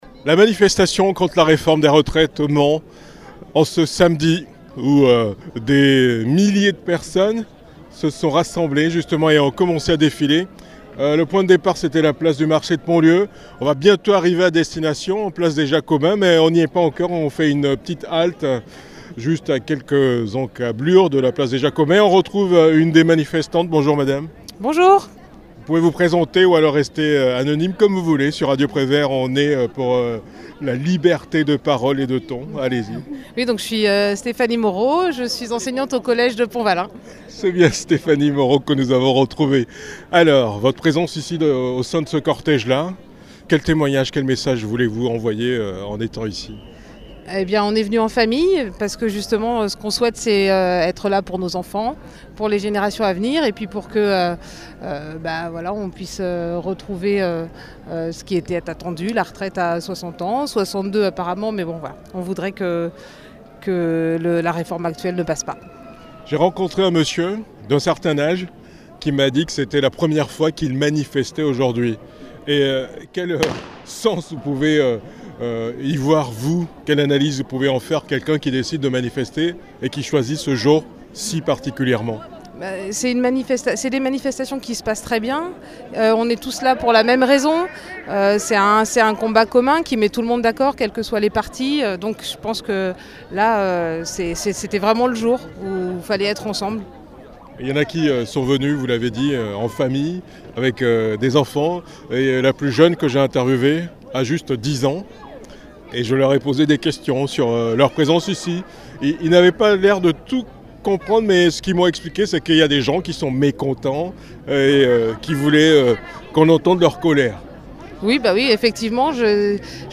11 février 2023 : manifestation contre la réforme des retraites au Mans